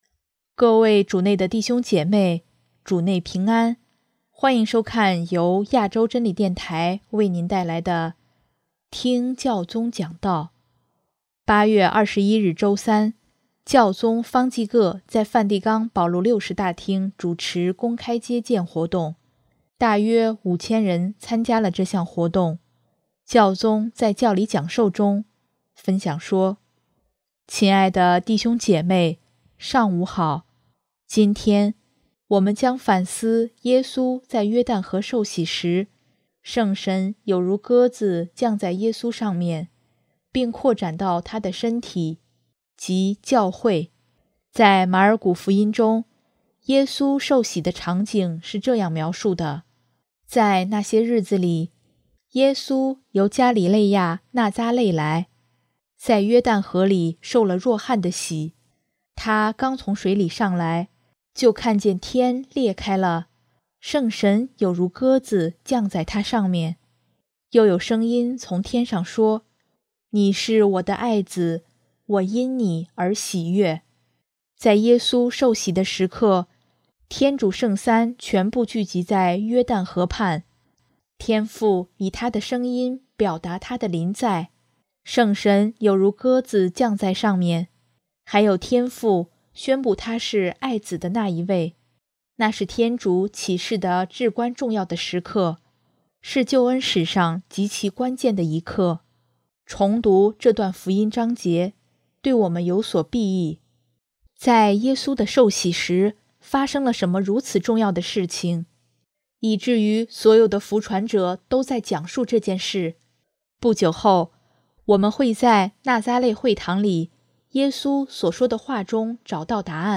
【听教宗讲道】|基督徒以仁爱与平安让世界散发基督的馨香
8月21日周三，教宗方济各在梵蒂冈保禄六世大厅主持公开接见活动，大约5千人参加了这项活动，教宗在教理讲授中，分享说：